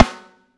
high snare f.wav